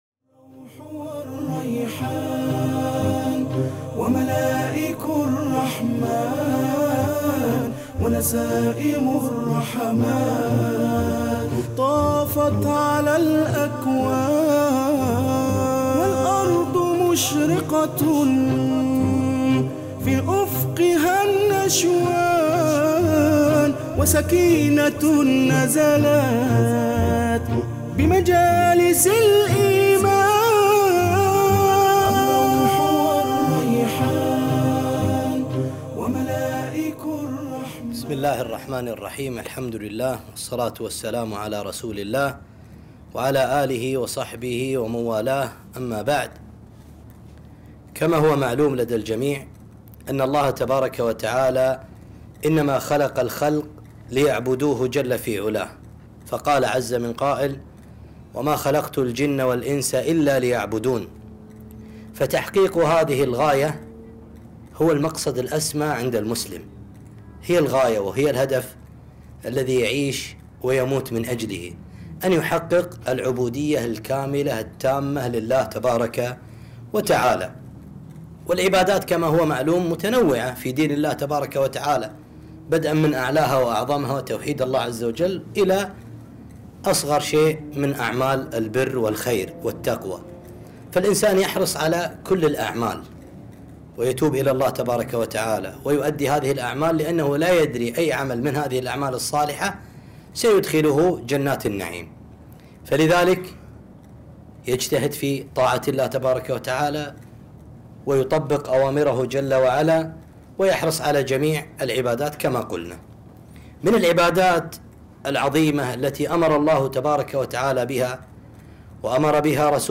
صلة الأرحام - محاضرة